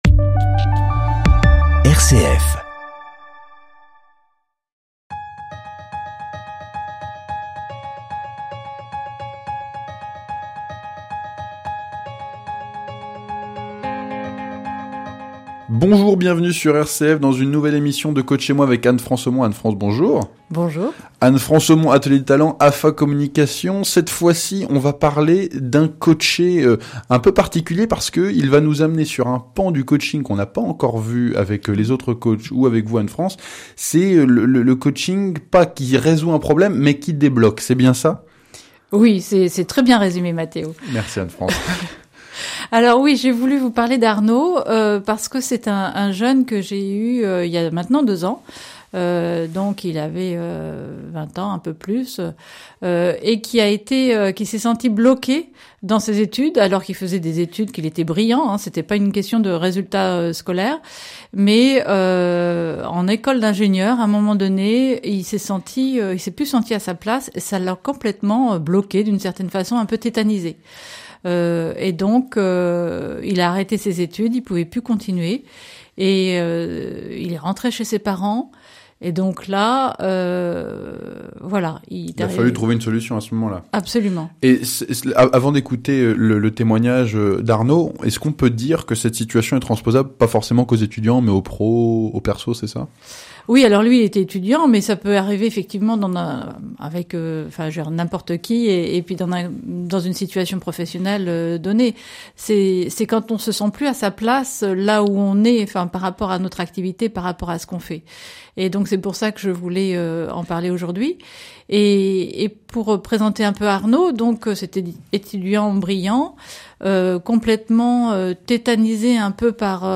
Genre : Radio.